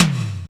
R BAMBTOMLO.wav